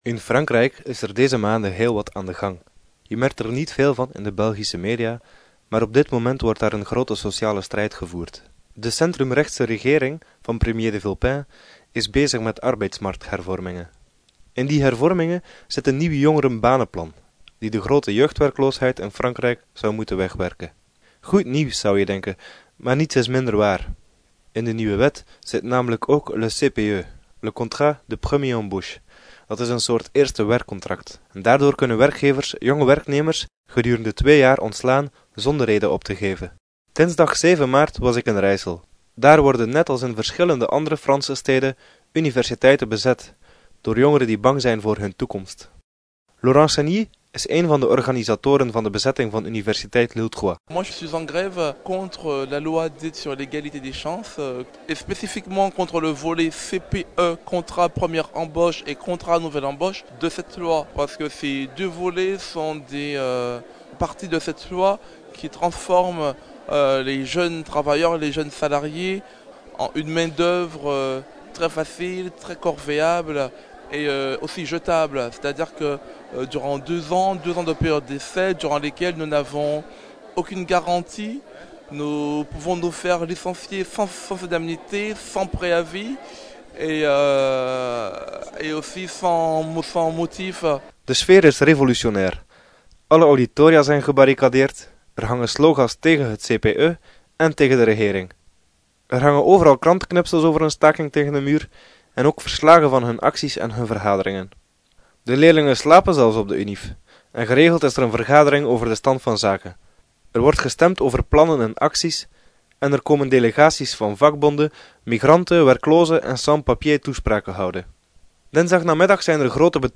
[Radioverslag] CPE NON Franse studenten op de bres tegen arbeidsmarkthervormingen
rijsel betoging cpe.mp3